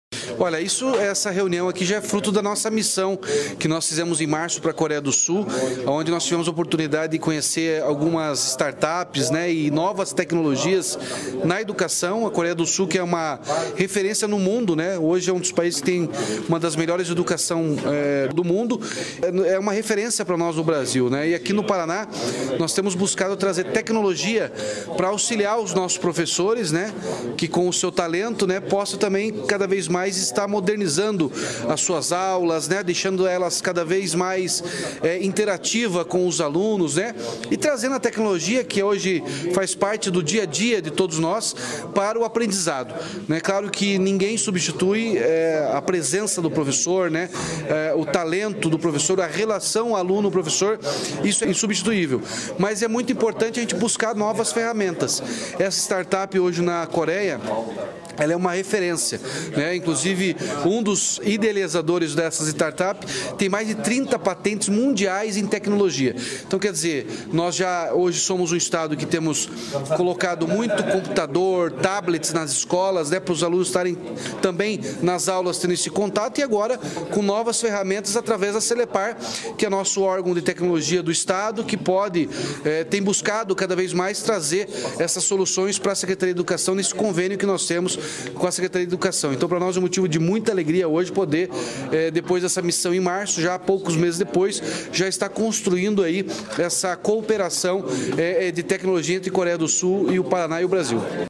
Sonora do Governador Ratinho Junior sobre a tecnologia sul-coreana no ensino paranaense
Carlos Massa Ratinho Junior - Governador do Paraná.mp3